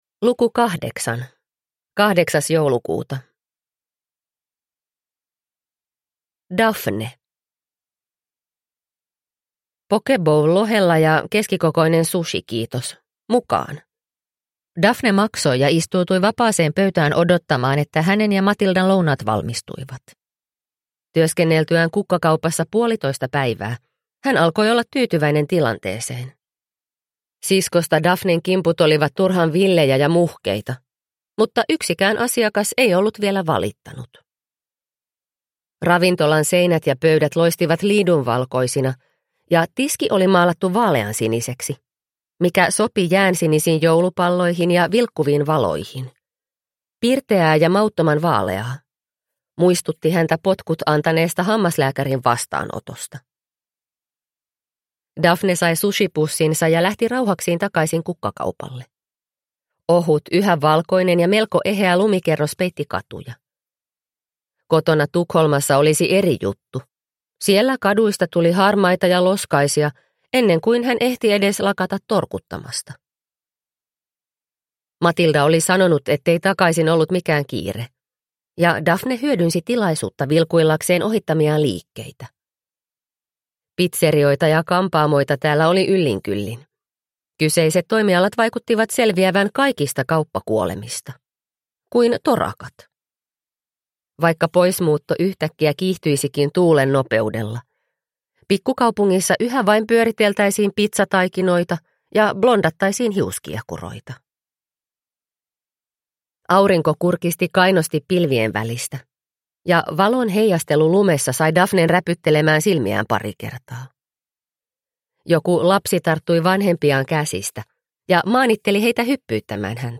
Sankt Annan joulu – Ljudbok – Laddas ner